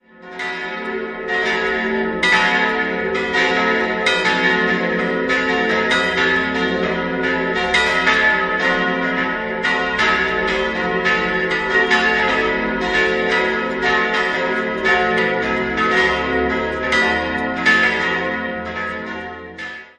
Die Pfarrkirche mit dem 45 Meter hohen Turm wurde im letzten Drittel des 17. Jahrhunderts errichtet. Im Lauf der Zeit kam es zu mehreren Umgestaltungen. 4-stimmiges Geläut: e'-fis'-a'-h' Die große Glocke wurde 1771 von Jacomini in Passau gegossen, Nr. 2 und 4 entstand 1951 bei Hofweber in Regensburg und Nr. 3 bei Hamm im Jahr 1946.